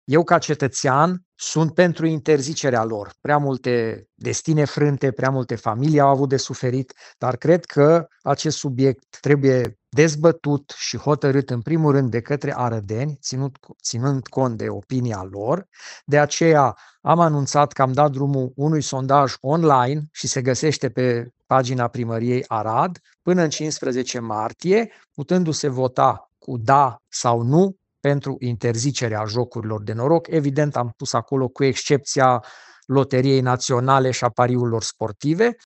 Primarul municipiului Arad, Călin Bibarț: „Prea multe destine frânte, prea multe familii au avut de suferit”